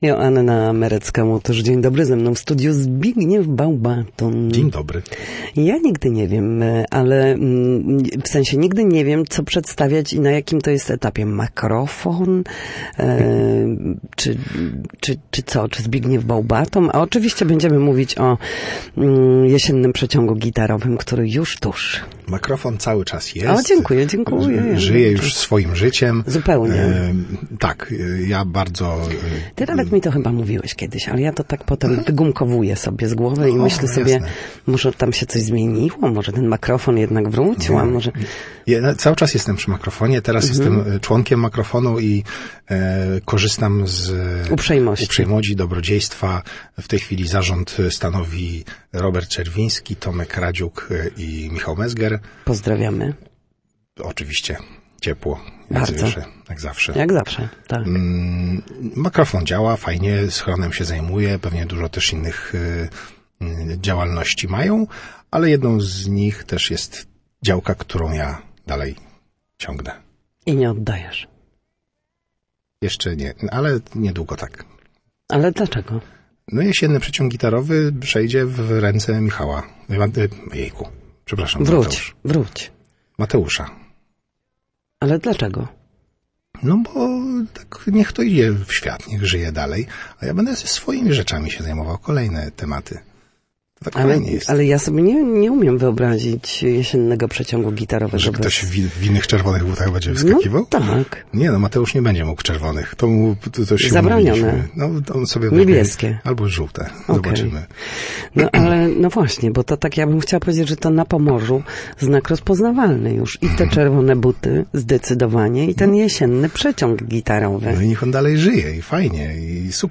mówił w Studiu Słupsk Radia Gdańsk